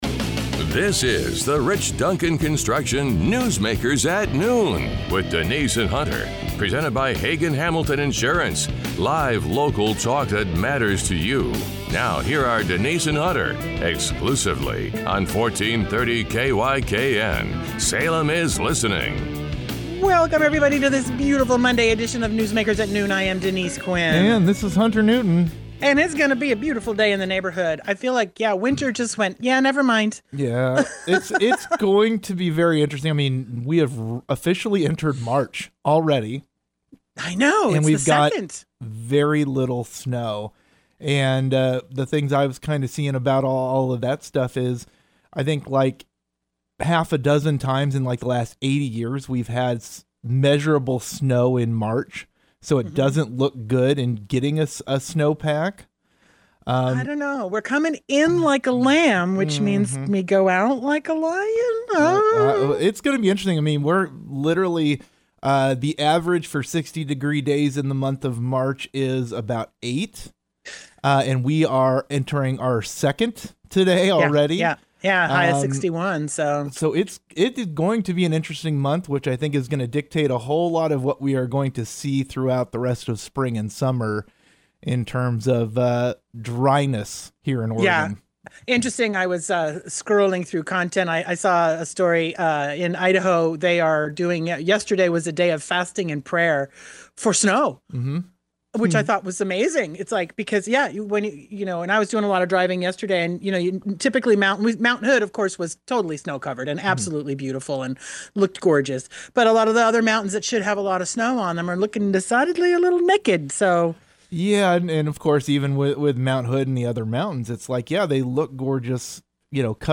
The conversation then turns to escalating tensions involving Iran and Operation Epic Fury, examining global reaction, oil market implications, congressional oversight questions, and the broader geopolitical impact. The hosts discuss national security concerns, public response, and how international events affect Americans at home.